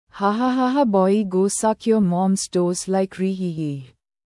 Sucking Toes Sound Effect Download: Instant Soundboard Button
sucking-toes.mp3